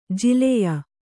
♪ jileya